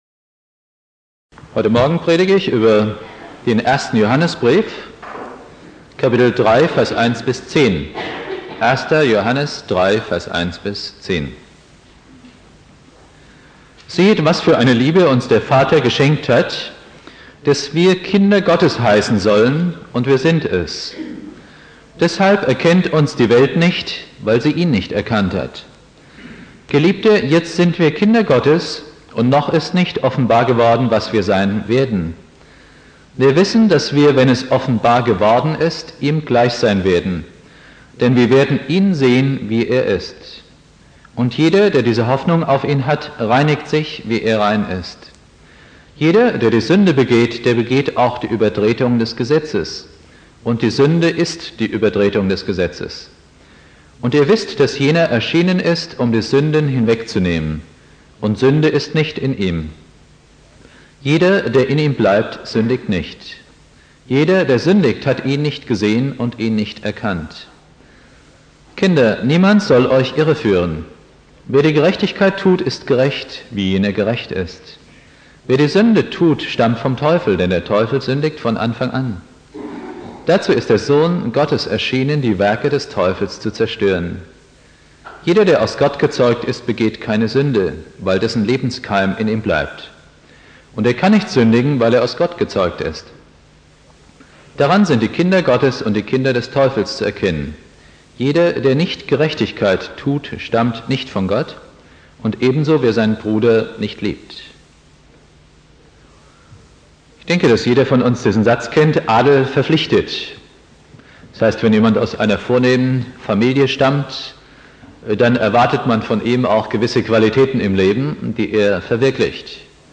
Predigt
1.Weihnachtstag
(schlechte Aufnahmequalität)